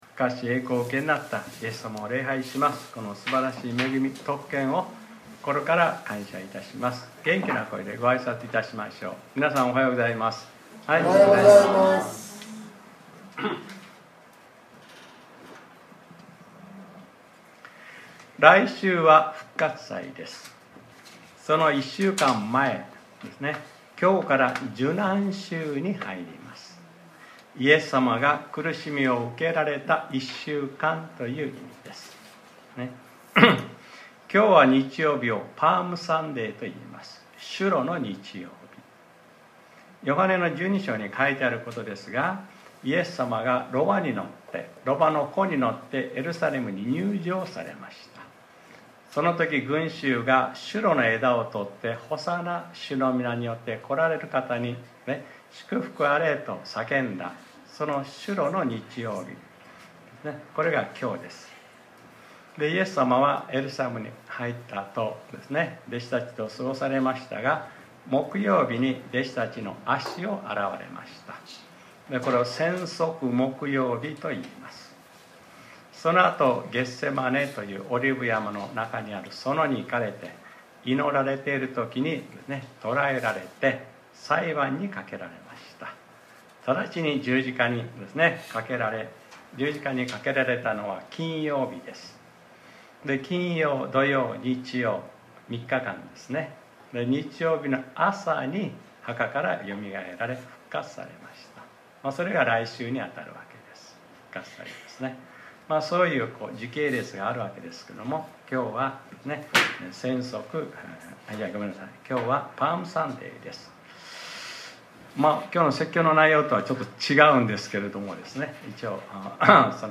2025年04月13日（日）礼拝説教『 神さまの働きはセルで行いましょう 』